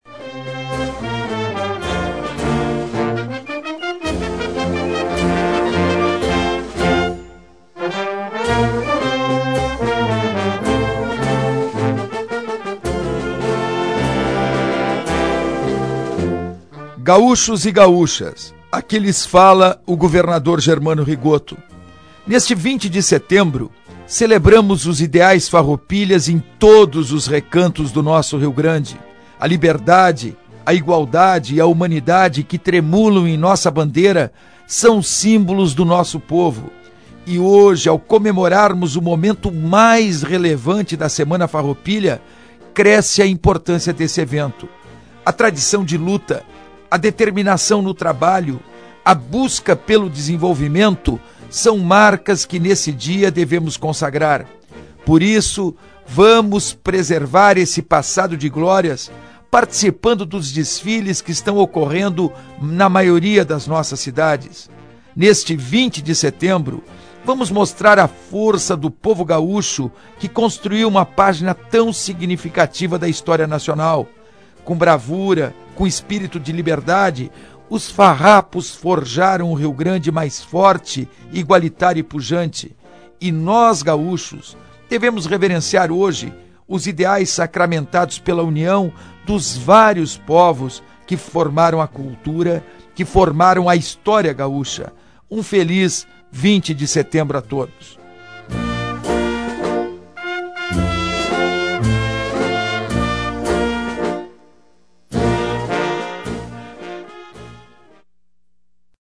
2006-09-19-mensagem-do-gov-rigotto-semana-farroupilha-2006-3.mp3